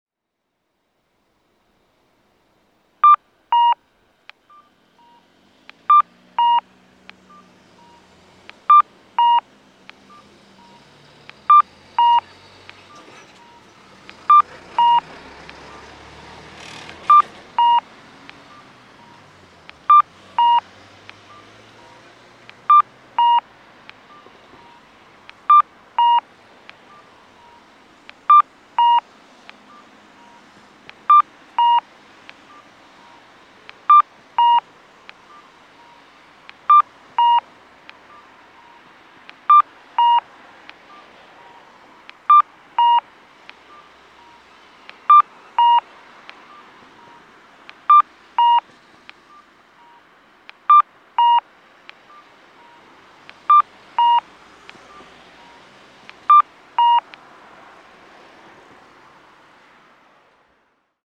間欠泉公園前(長野県諏訪市)の音響信号を紹介しています。